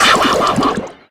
Cries
BRUXISH.ogg